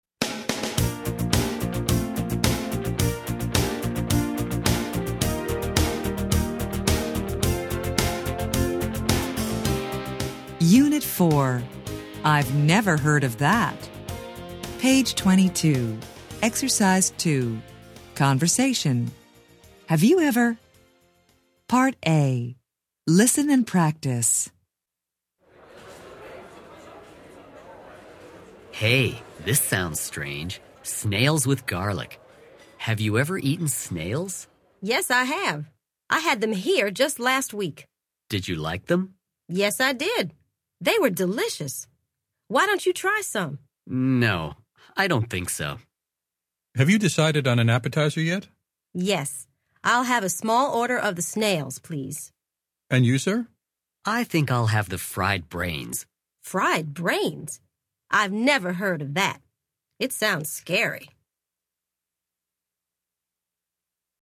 American English
Interchange Third Edition Level 2 Unit 4 Ex 2 Conversation Track 7 Students Book Student Arcade Self Study Audio